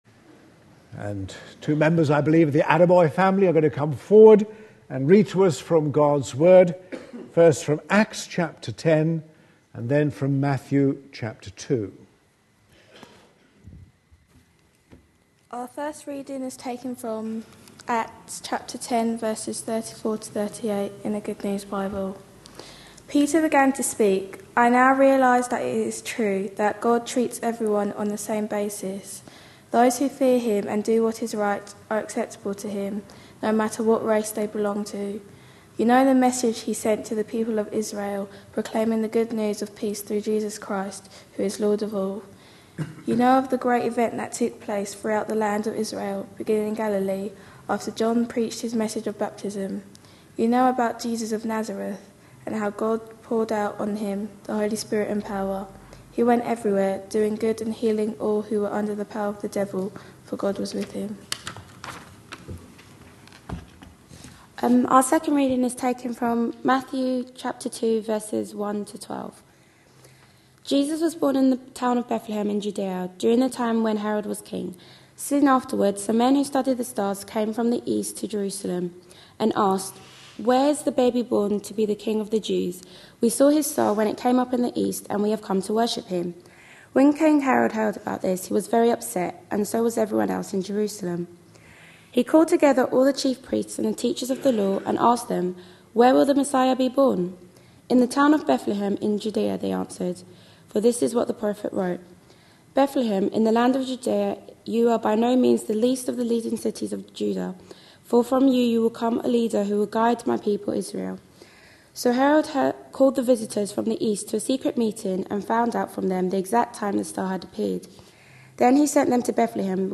A sermon preached on 18th December, 2011.